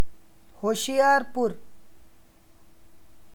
Hoshiarpur (Punjabi: [ɦʊʃɪˈaːɾpʊɾ]